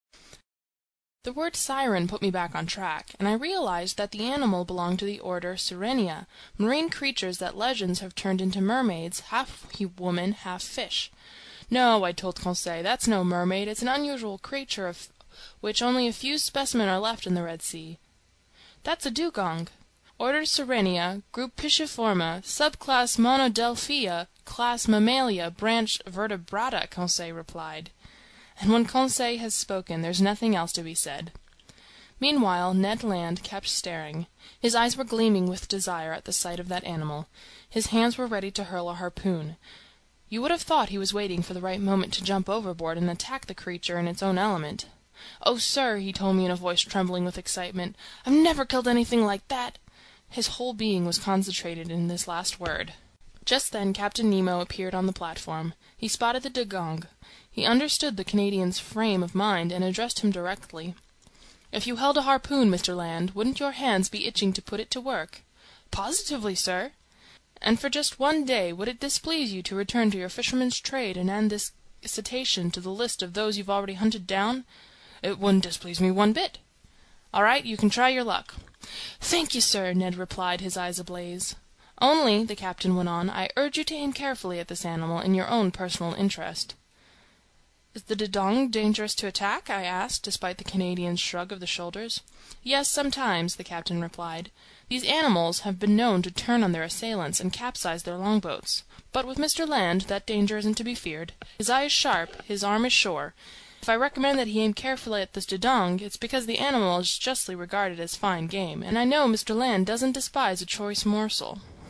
在线英语听力室英语听书《海底两万里》第359期 第23章 珊瑚王国(56)的听力文件下载,《海底两万里》中英双语有声读物附MP3下载